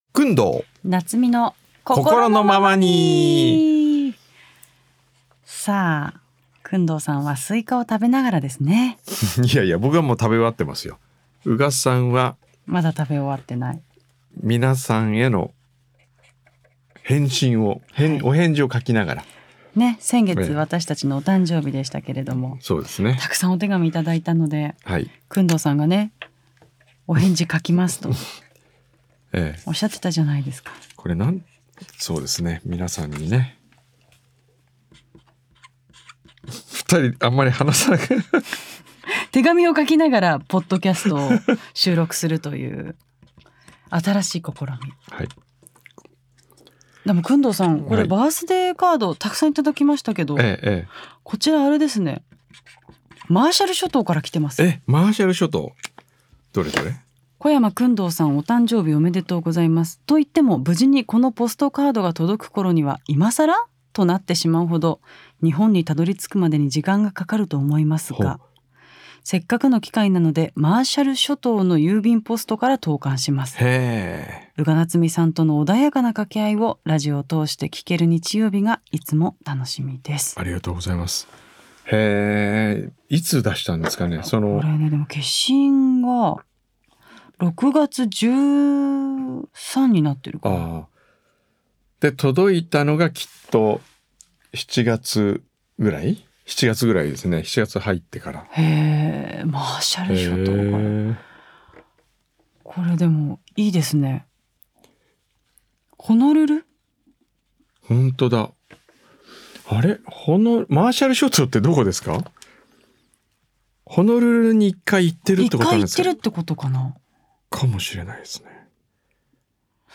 小山薫堂と宇賀なつみが「SUNDAY’S POST」の番組内で紹介しきれなかった手紙やメッセージをご紹介しています。